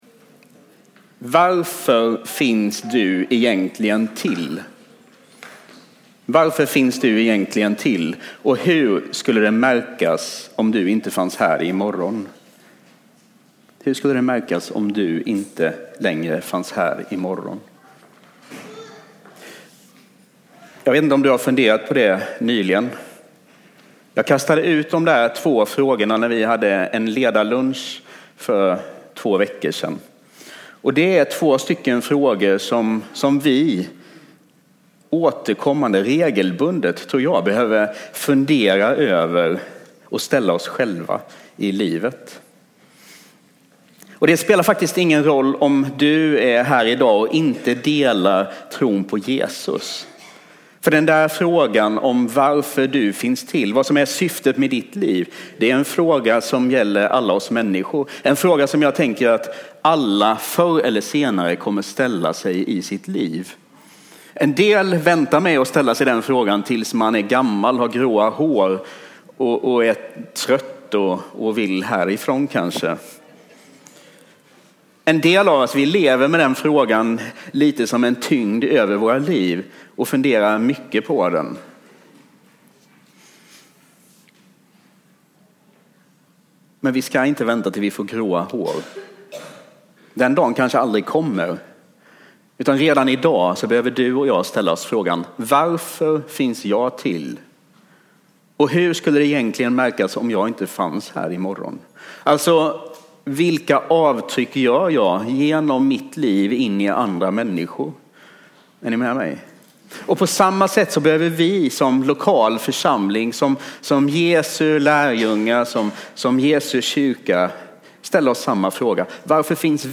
A predikan from the tema "APG."